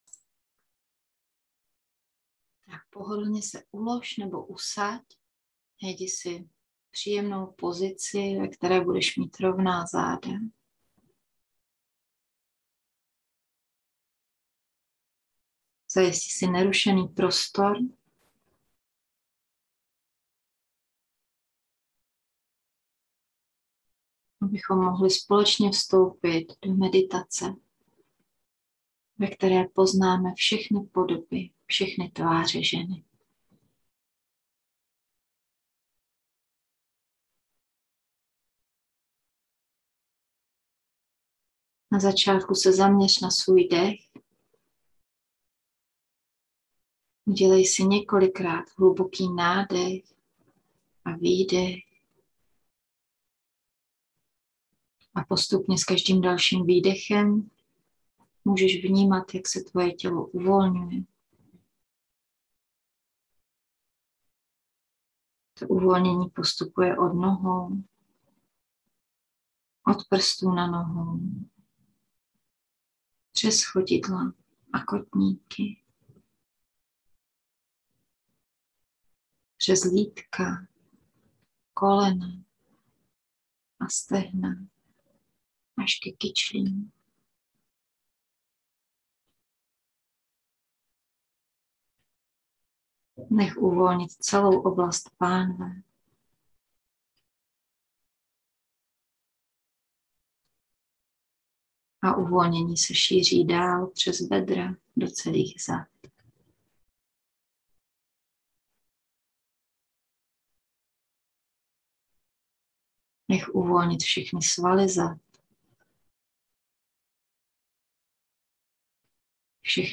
meditace_VsechnyTvareZeny.mp3